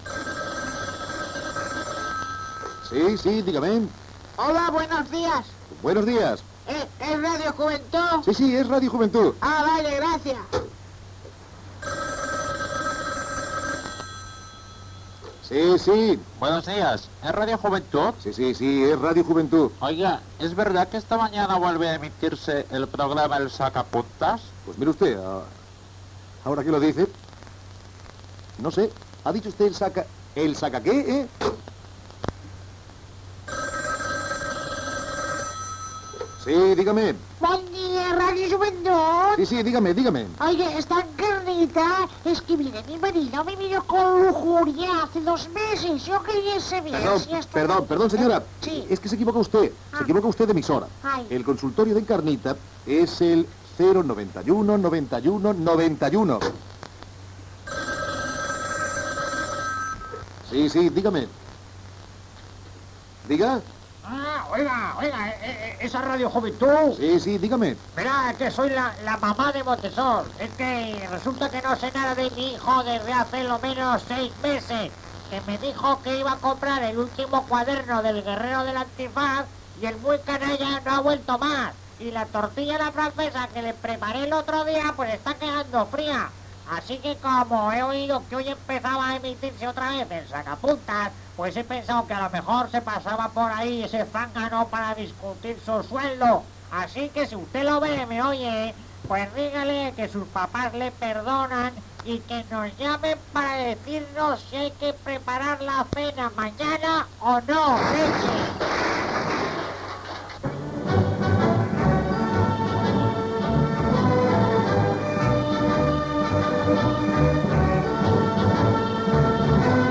Indicatiu del programa, comentari sobre la temporada i els horaris d'emissió, Diversos personatges visiten l'equip del programa.